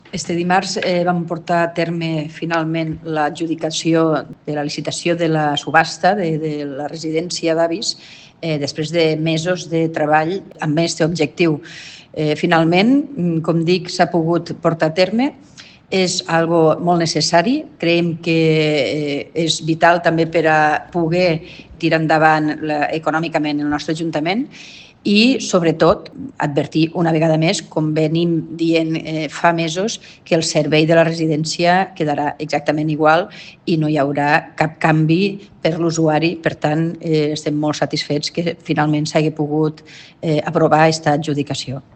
L’alcaldessa de l’Ametlla de Mar, Eva del Amo, explica l’acord de venda de la residència de la gent gran i reitera que l’operació no alterarà el servei